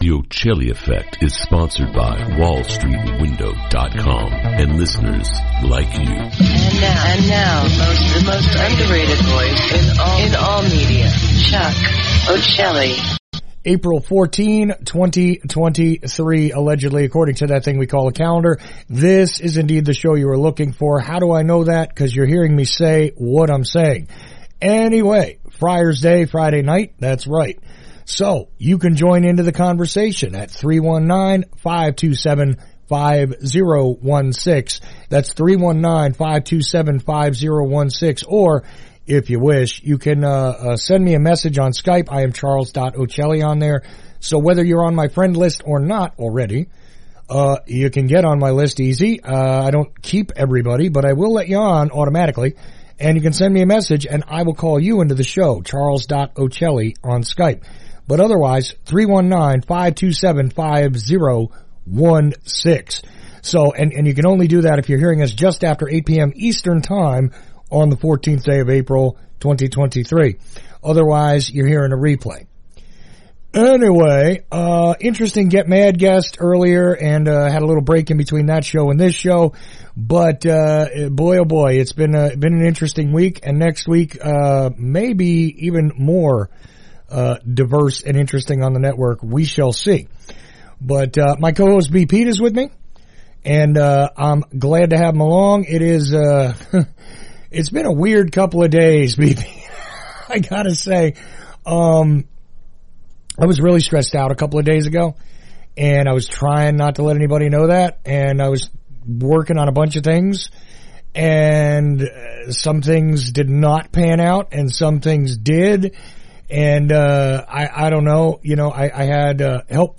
Roundtable
Another Friday Night Open Mic gets done with and a handful of callers covered a few subjects.